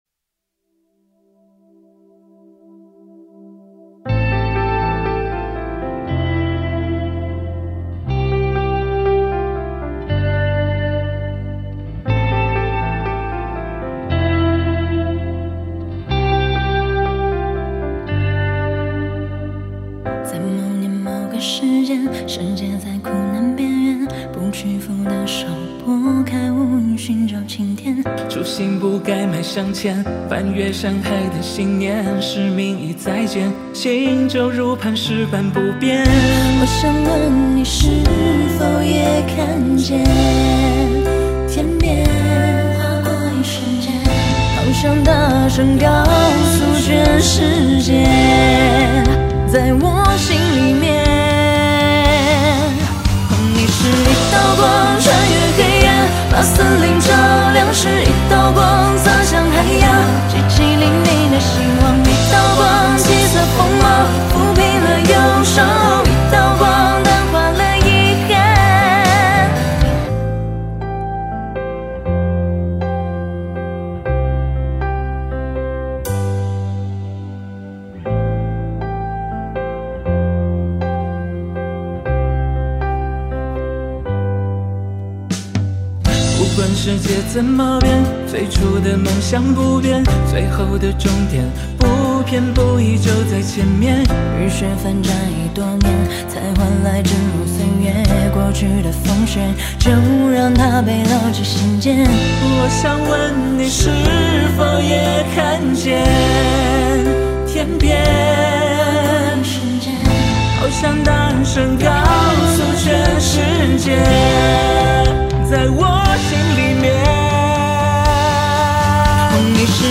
轻快活泼的曲风